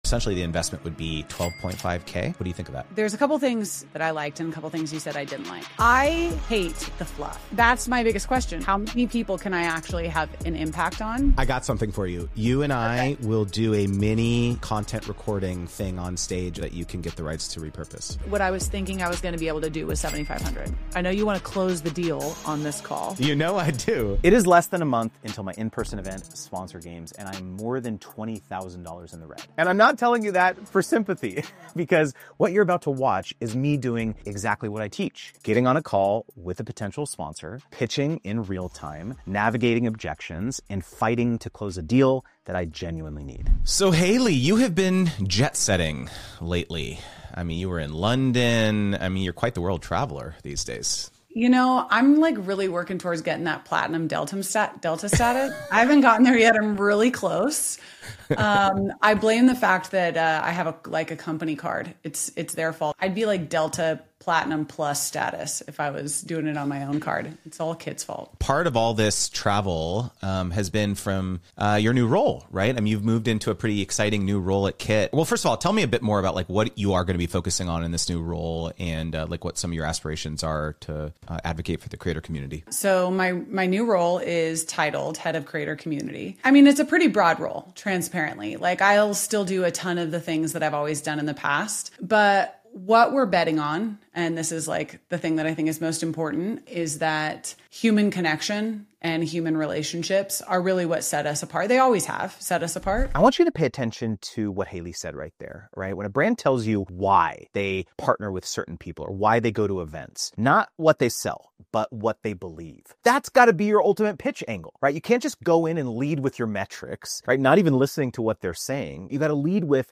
Two calls.
Zero polish.